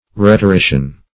Rhetorician \Rhet`o*ri"cian\, a.